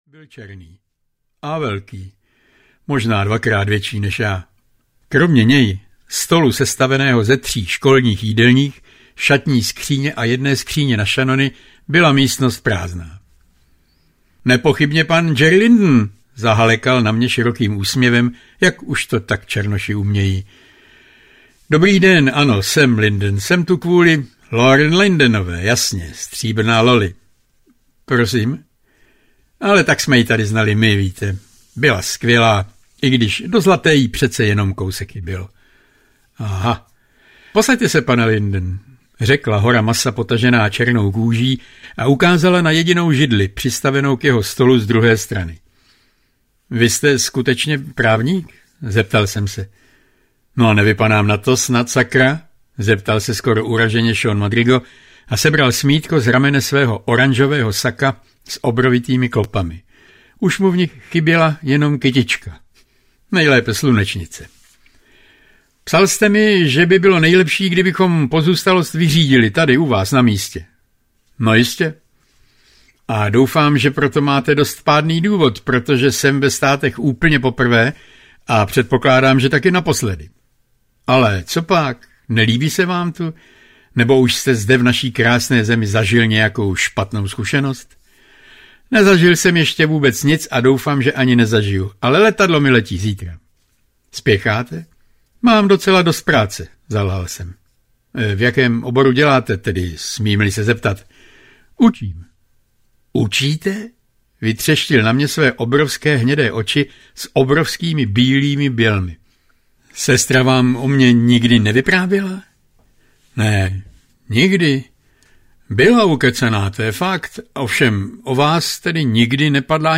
Nevermore Baltimore audiokniha
Ukázka z knihy